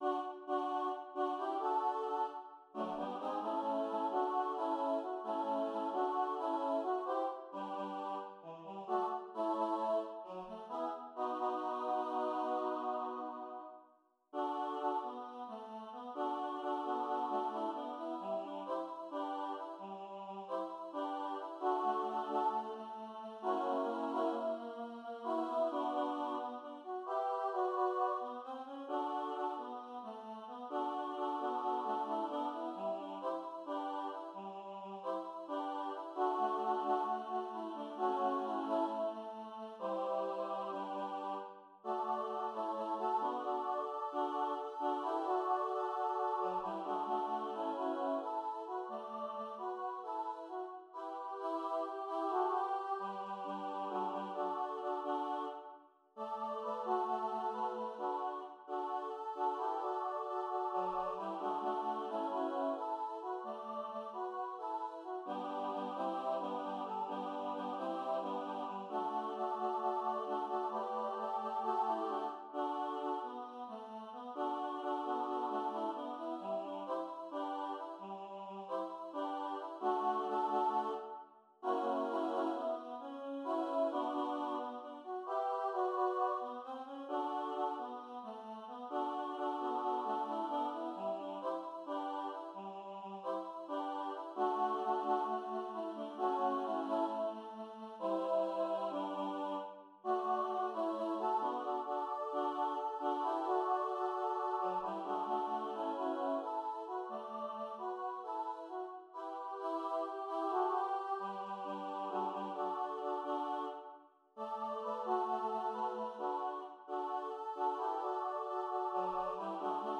kan synges både a cappella og med akkompagnement